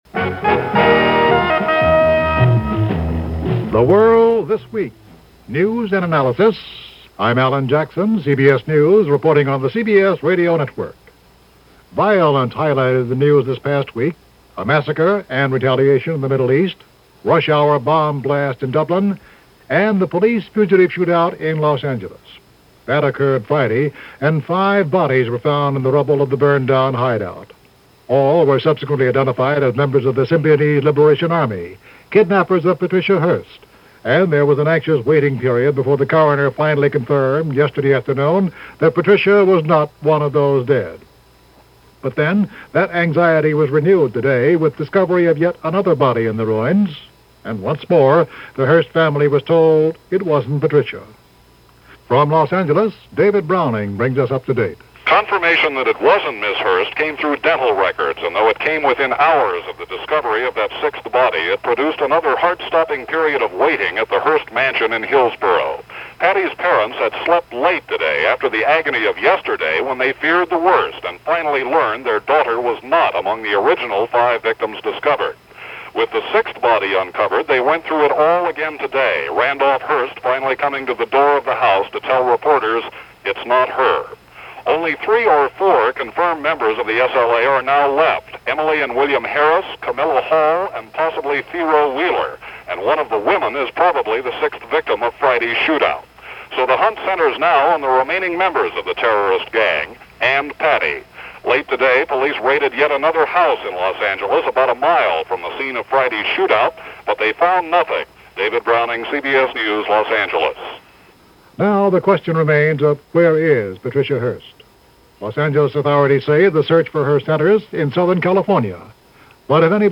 And that’s how the violence for this week unfolded, the week ending May 19, 1974 as reported on The World This Week from CBS Radio.